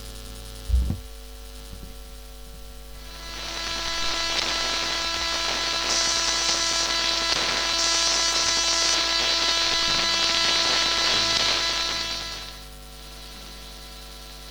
Da es eine Fender Mexico Strat mit Singlecoils ist, bekomm ich da ziemlich nerviges Surren auf die Aufnahmen.
Zuerst das typische Coil-Brummen und dann beim Drehen zum Rechner das hässliche Zischeln, was sich auch beim Ändern der Pickups nicht verbessert.